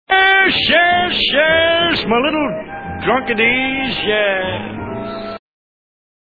Bobby also demonstrated his versatility in this hour with his singing, impressions, acting and playing the guitar and piano.